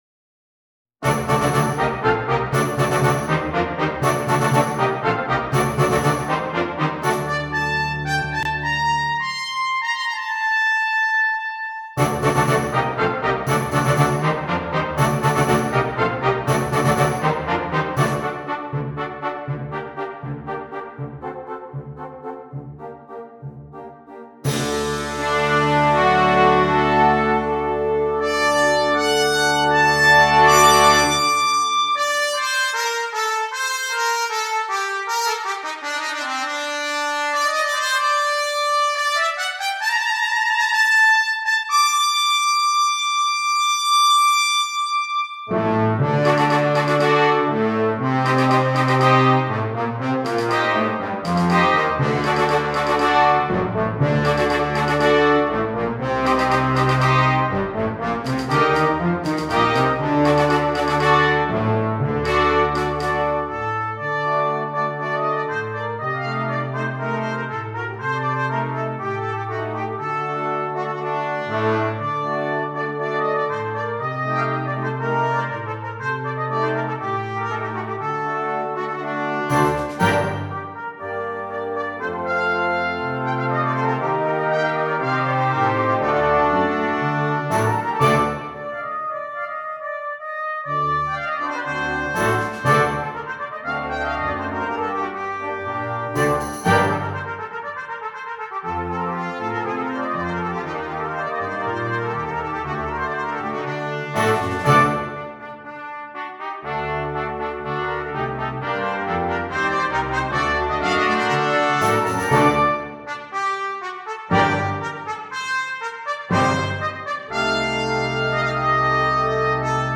Brass Band
Traditional Spanish
traditional bullfighting song